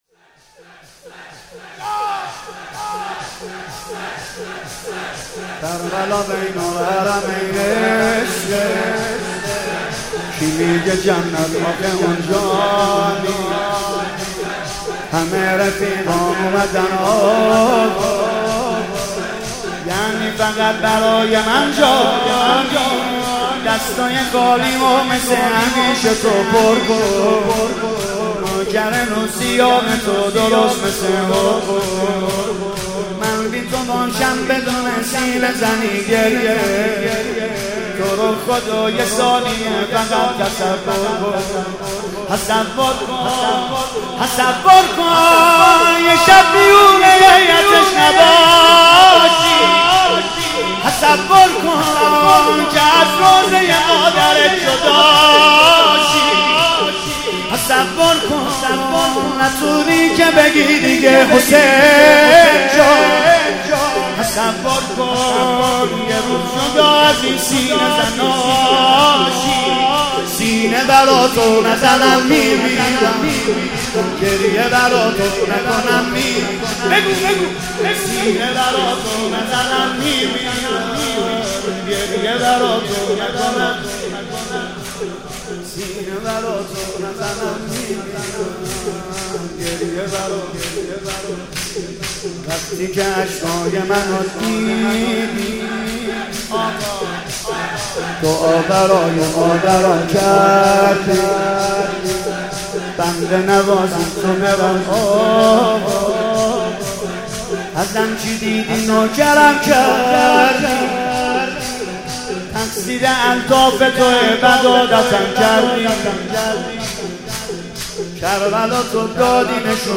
«ویژه مناسبت تخریب بقیع» شور: کربلا بین الحرمین عشقه